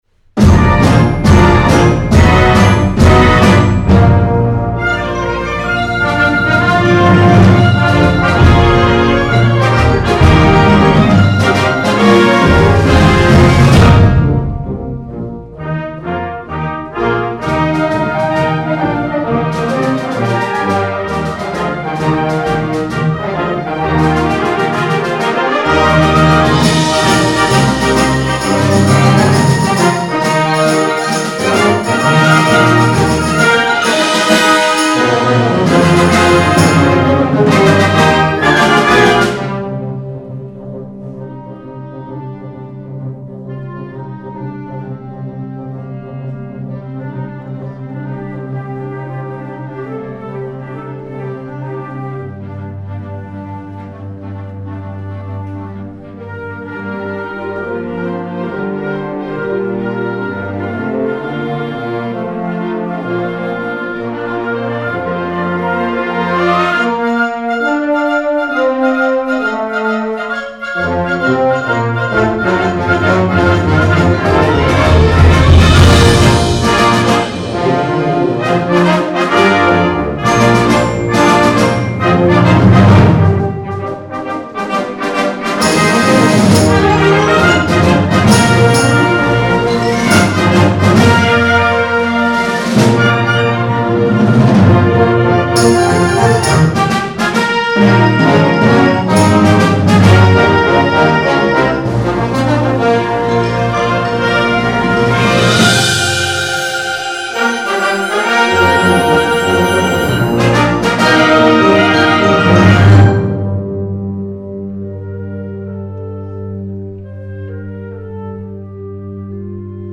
Genre: Band
Piccolo
Tuba
Timpani (4)